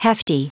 Prononciation du mot hefty en anglais (fichier audio)
hefty.wav